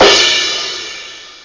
.8crashcym.mp3